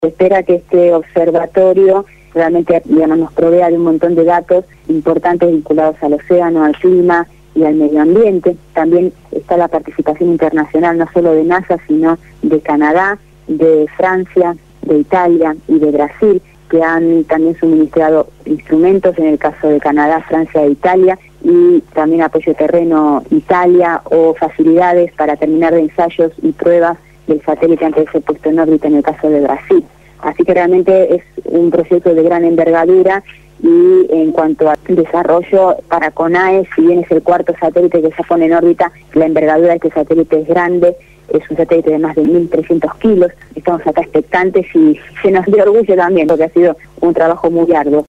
habló en Radio Gráfica FM 89.3 la mañana del viernes, antes del lanzamiento del satélite